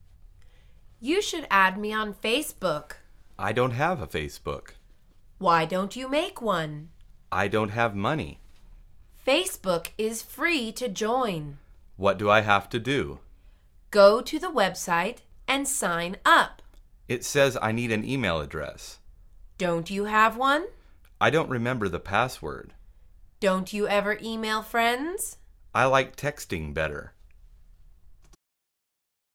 مجموعه مکالمات ساده و آسان انگلیسی – درس شماره دوم از فصل شبکه اجتماعی: پیوستن به فیس بوک